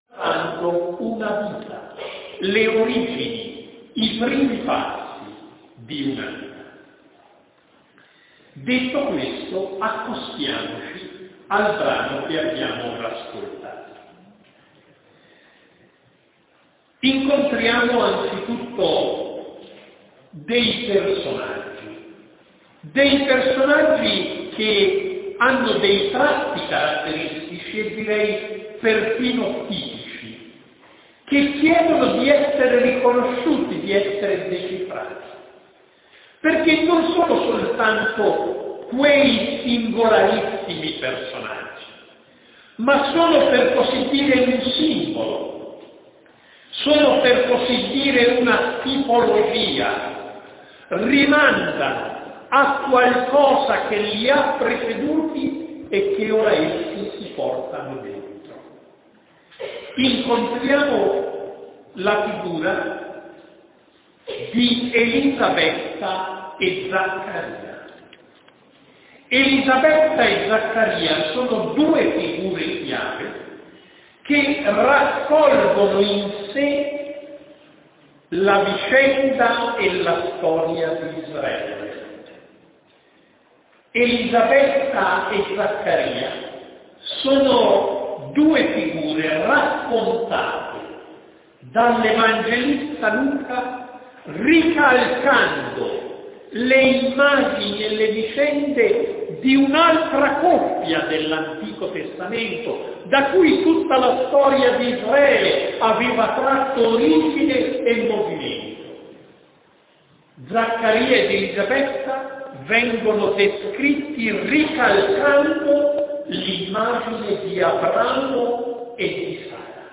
Con il Convegno d’inizio anno del 20 settembre, caratterizzato dalla presenza dell’assistente generale nazionale mons. Mansueto Bianchi, è iniziato l’anno associativo 2015-2016. In questa pagina l’audio della lectio di Bianchi sull’icona biblica dell’anno, la programmazione diocesana, i materiali dei settori e le priorità che ci siamo dati.
L’audio dovrebbe essere abbastanza chiaro, ma per l’uso formativo consigliamo di riascoltare con attenzione e trascrivere quanto ritenuto di maggiore interesse.
Lectio mons. Bianchi